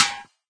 metal3.ogg